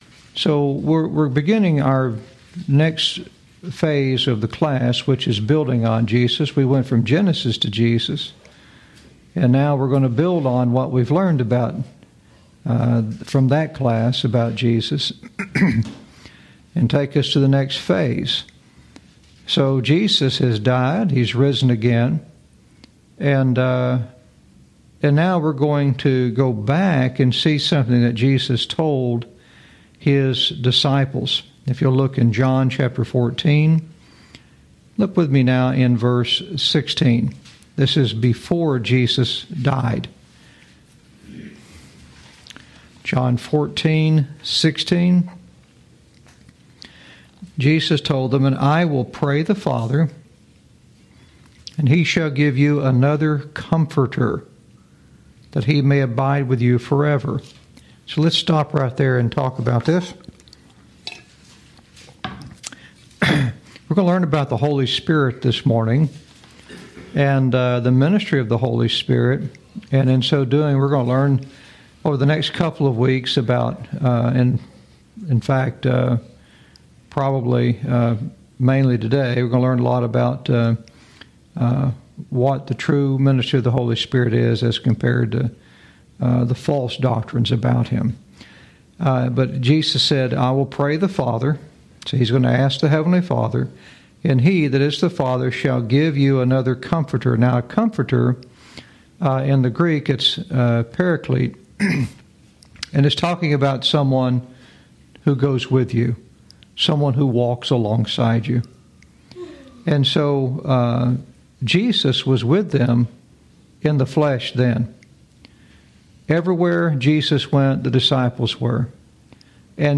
Lesson 1